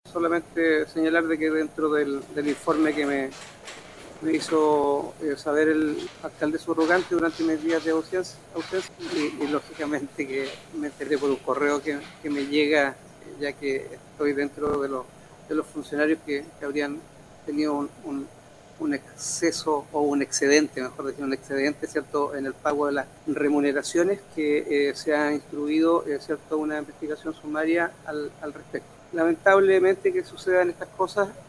Así lo indicó en la sesión de Concejo de este lunes 30 de julio, en la que informó que se instruyó esta medida, para poder determinar si eventualmente existirían responsabilidades en este caso.